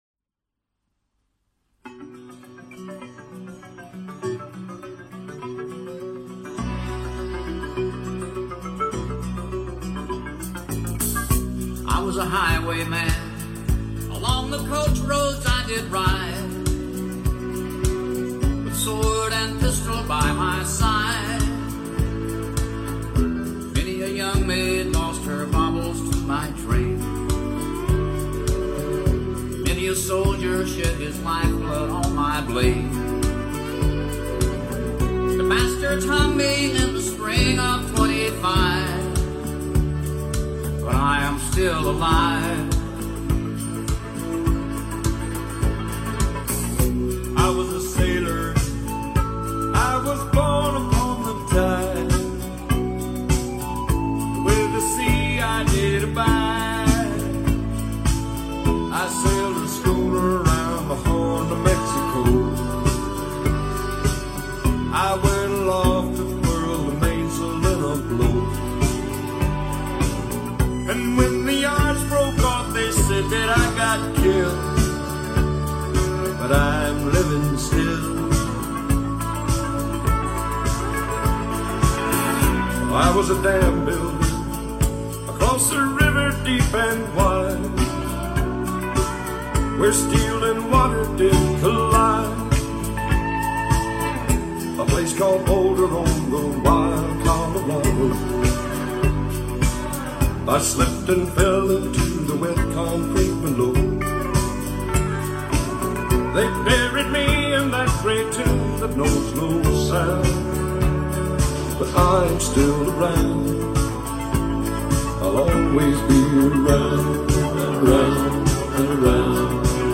Outlaw Country, Country, Folk